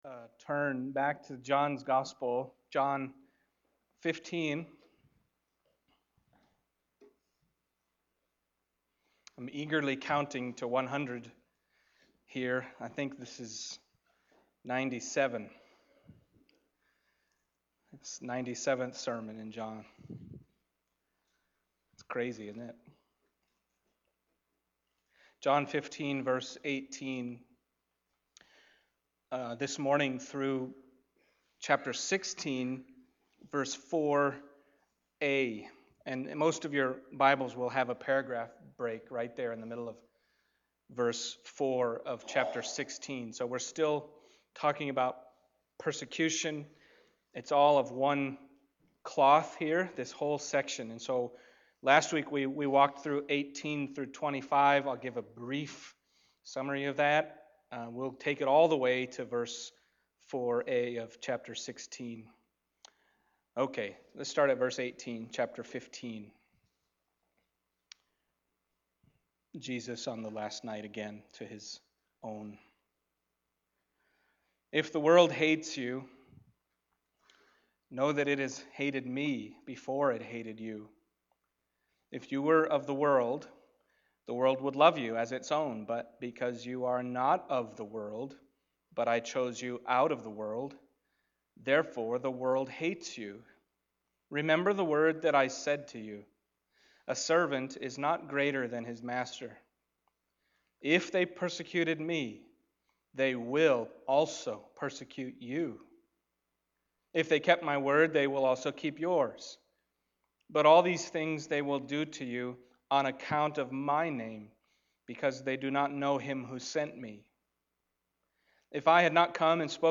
John Passage: John 15:18-16:4 Service Type: Sunday Morning John 15:18-16:4a « The World Hated Jesus …